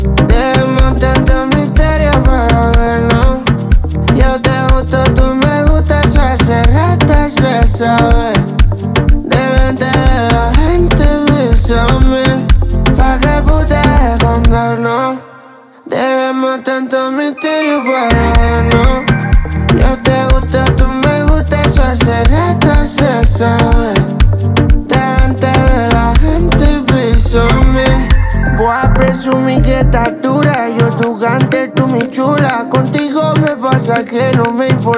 Жанр: Поп / Латиноамериканская музыка / Африканская музыка